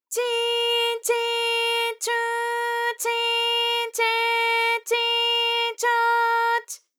ALYS-DB-001-JPN - First Japanese UTAU vocal library of ALYS.
chi_chi_chu_chi_che_chi_cho_ch.wav